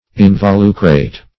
Meaning of involucrate. involucrate synonyms, pronunciation, spelling and more from Free Dictionary.
Search Result for " involucrate" : Wordnet 3.0 ADJECTIVE (1) 1. having an involucre ; The Collaborative International Dictionary of English v.0.48: Involucrate \In`vo*lu"crate\, Involucrated \In`vo*lu"cra*ted\, a. (Bot.)